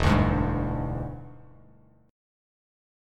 E7#9 chord